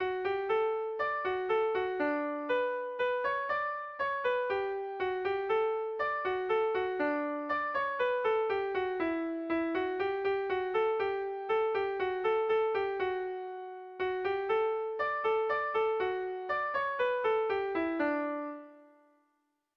Erlijiozkoa
A1A2BA2